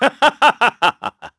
Clause_ice-Vox_Happy3_kr.wav